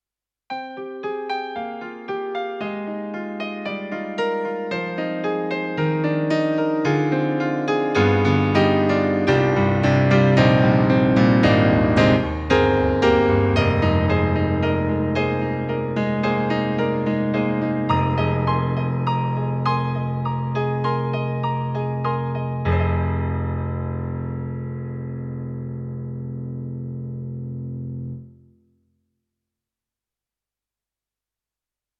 PIANO
KORG_SP-100_PIANO.mp3